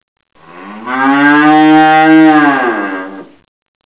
Cow
COW.wav